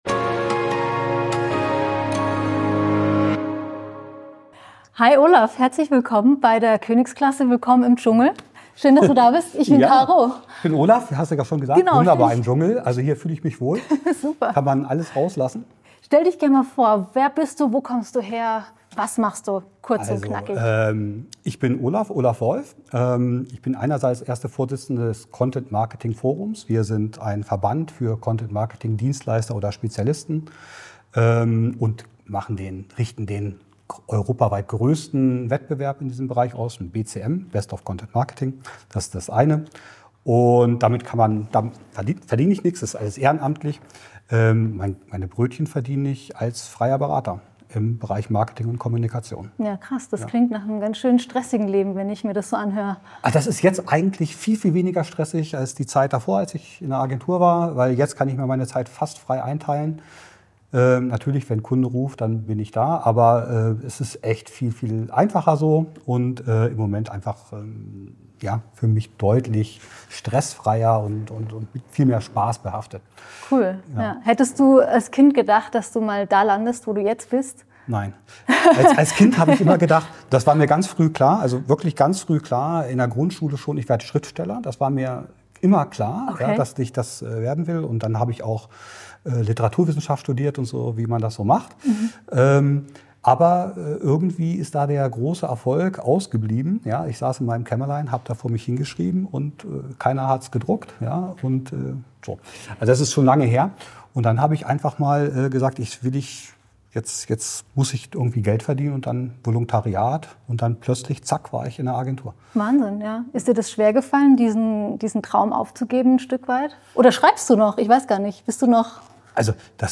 KÖNIGSKLASSE - Die Gen Z interviewt CXOs über ihr Leben: